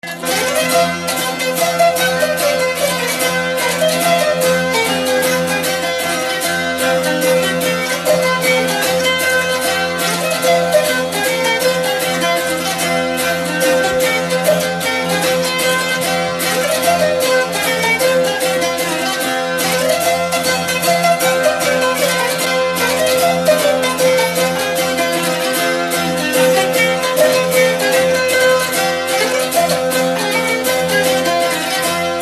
Dallampélda: Hangszeres felvétel
Alföld - Bihar vm. - Sarkad
citera Műfaj: Csárdás Gyűjtő